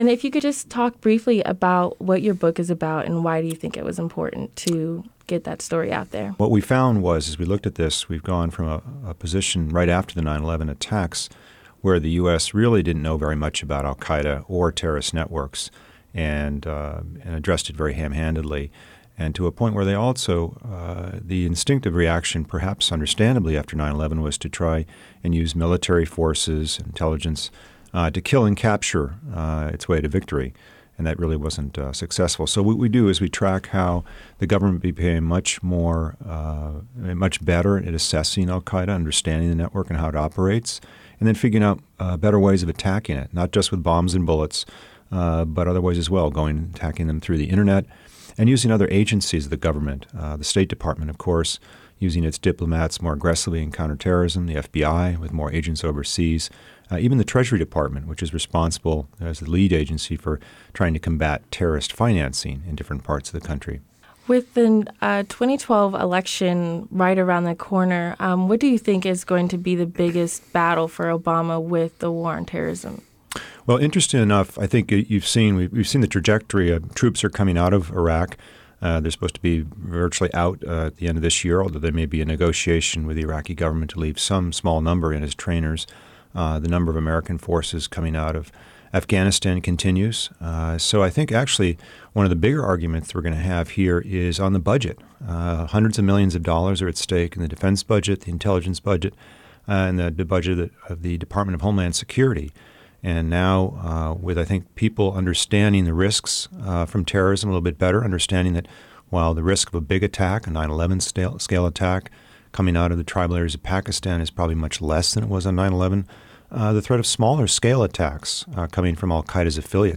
Today I talked with Eric Schmitt about the book.